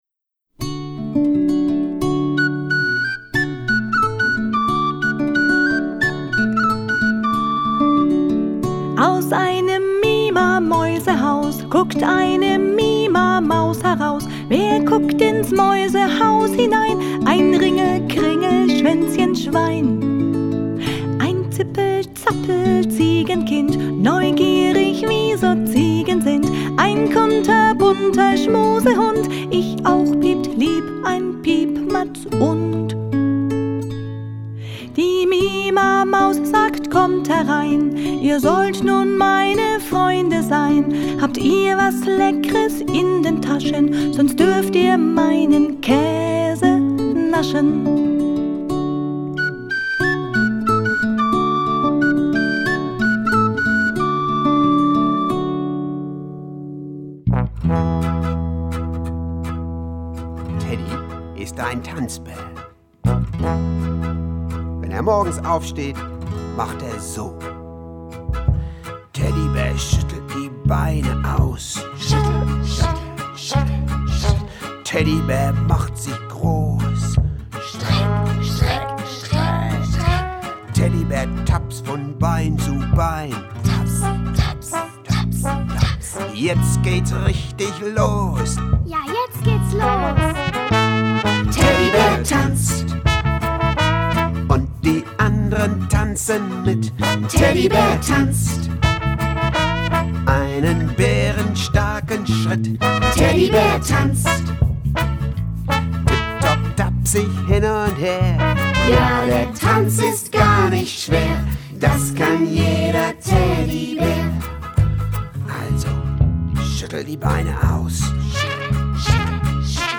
Themenwelt Kinder- / Jugendbuch Gedichte / Lieder